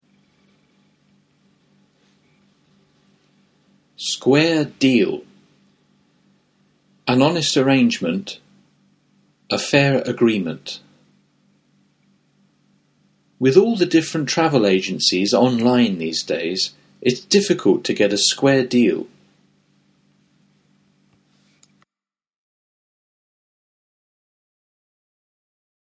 ネイティブによる発音は下記のリンクをクリックしてください。